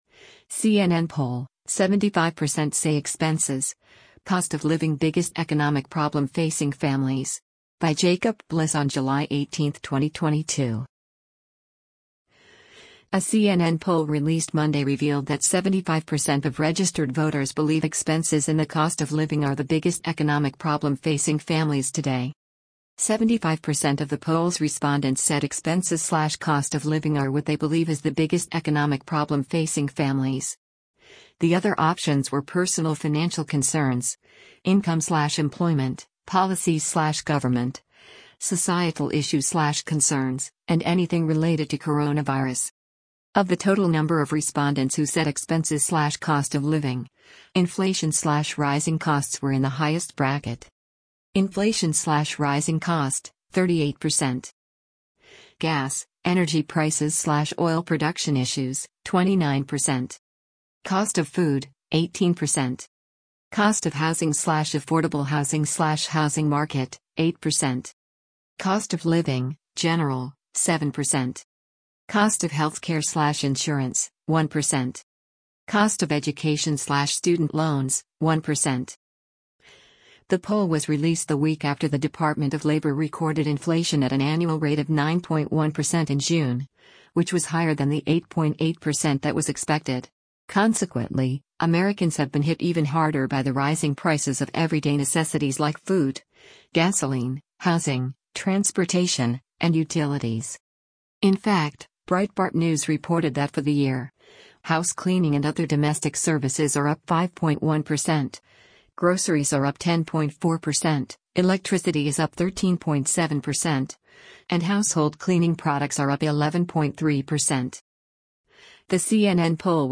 US President Joe Biden speak about the economy and inflation from the deck of the USS Iowa